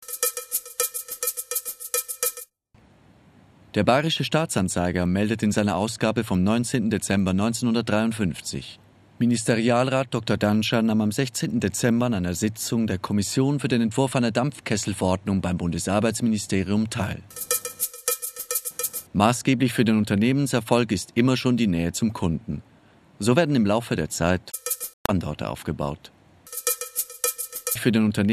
Sprecherdemos